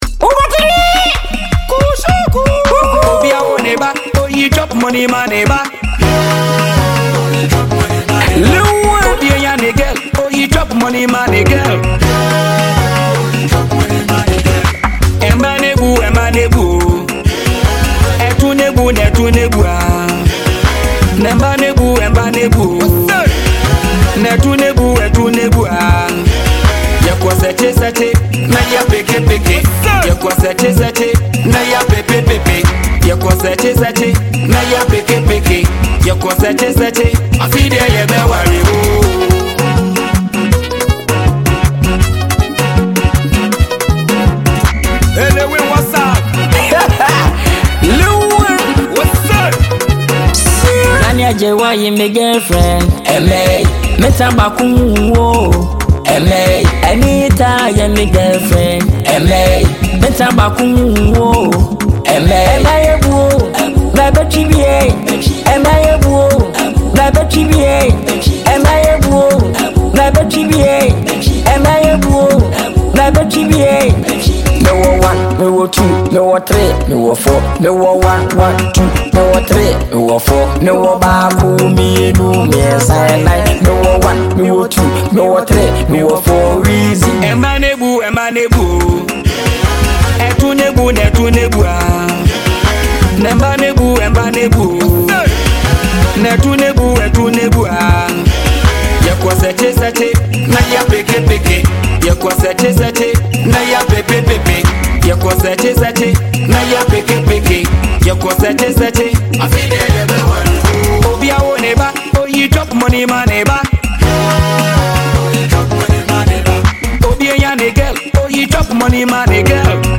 hilarious and energetic new song